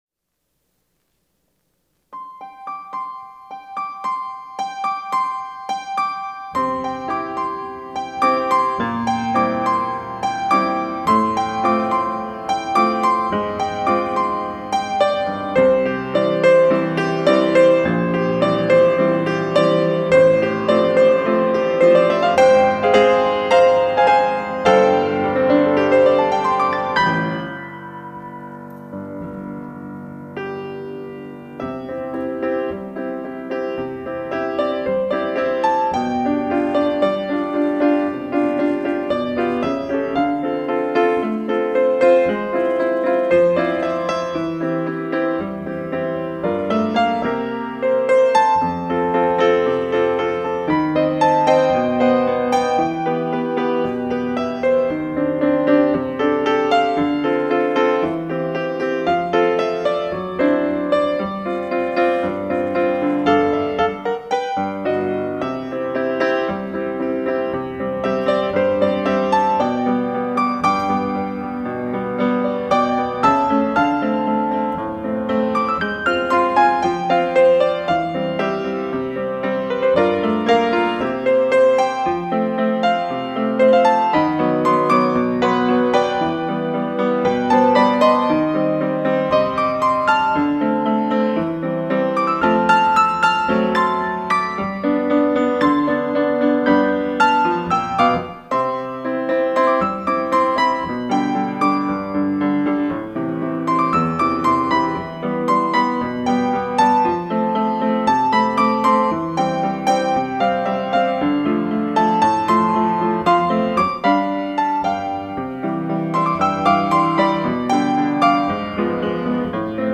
특송과 특주 - 야곱의 축복